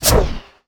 MAGIC_SPELL_Spawn_mono.wav